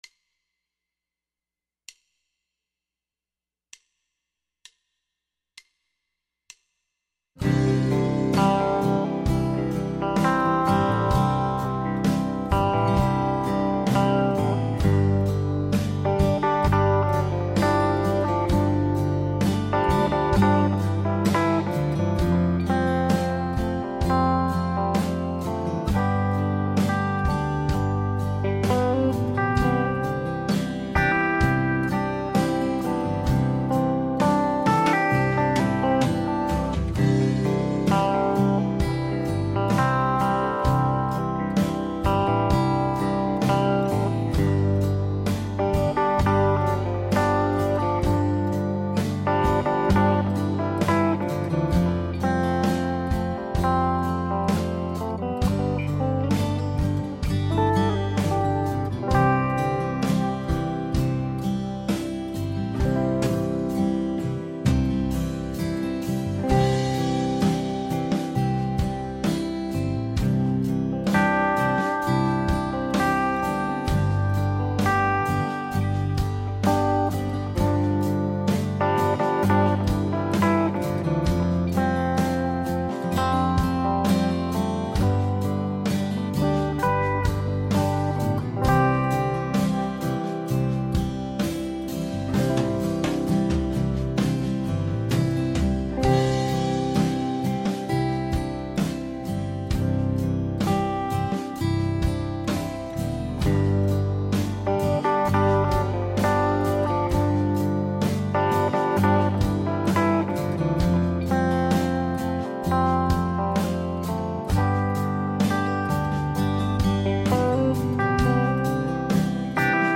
В аккомпанементе звучит 6 квадратов.